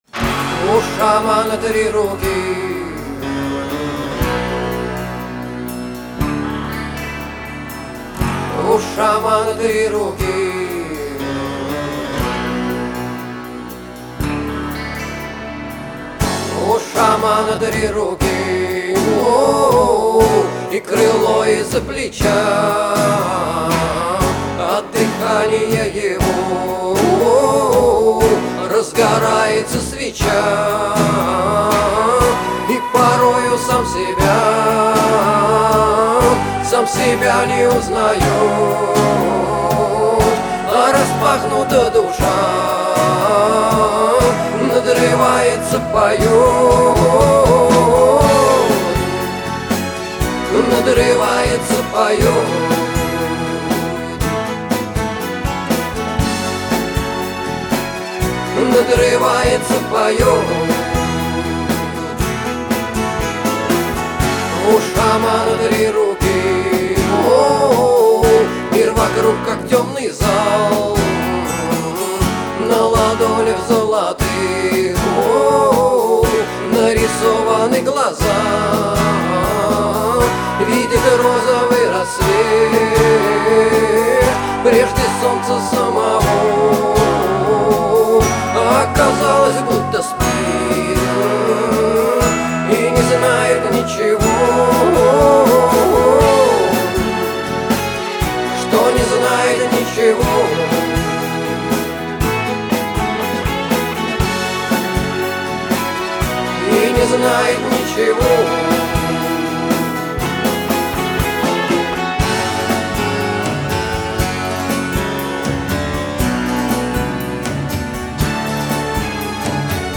Стиль: Rock
Тэги: Art Rock